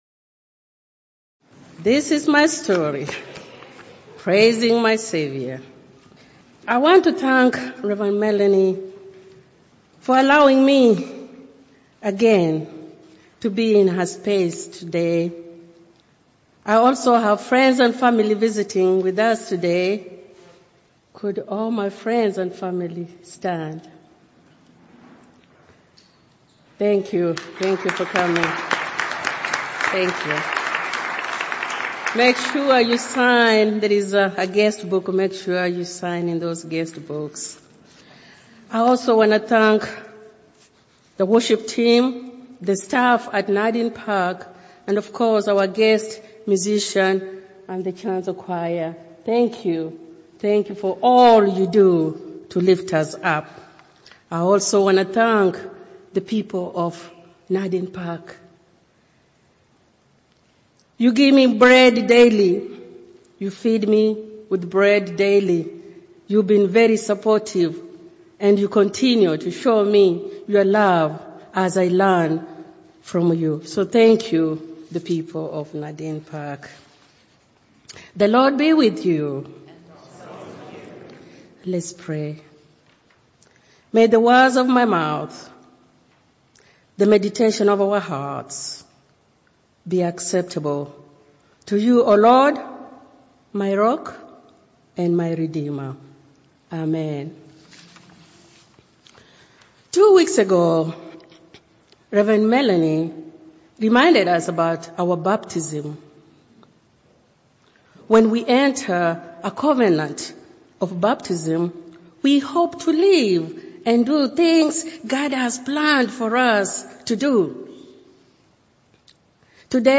Jan2719-Sermon.mp3